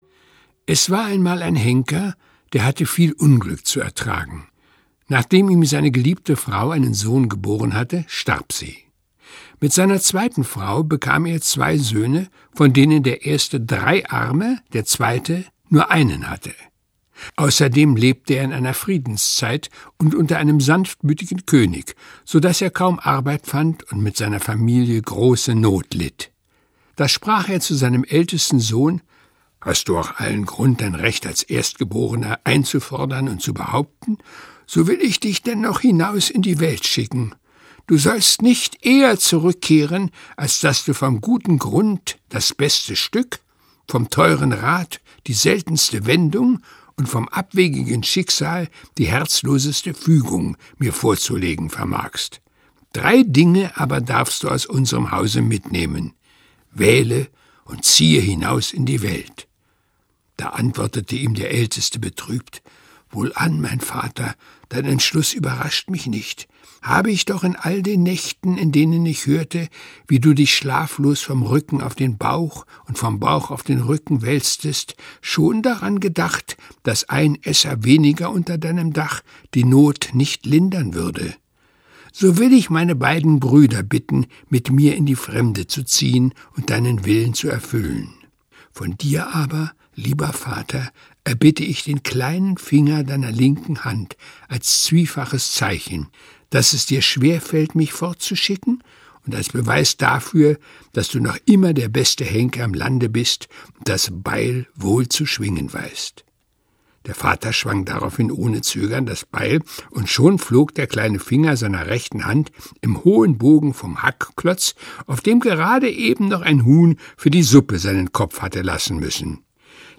Lese- und Medienproben